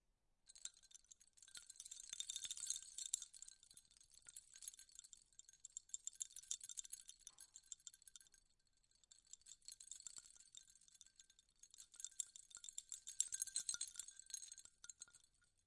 风铃
描述：塑料风铃被震动，如果它被风或微风吹动。没有风声。在户内用Zoom H6录制。
Tag: 震动 移动 微风 OWI